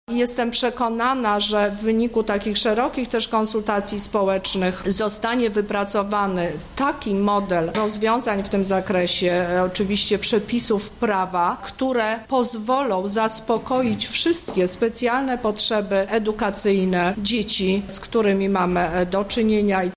– mówi Teresa Misiuk, lubelska kurator oświaty